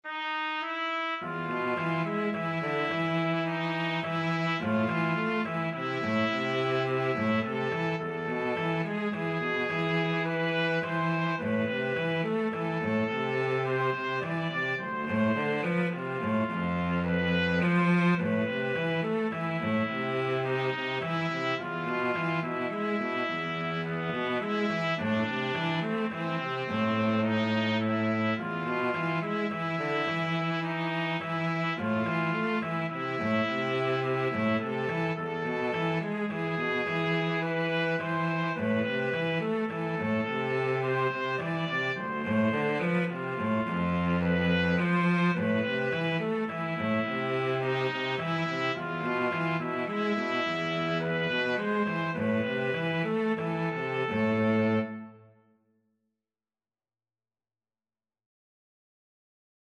3/4 (View more 3/4 Music)
Slow Waltz = c. 106
Traditional (View more Traditional Trumpet-Cello Duet Music)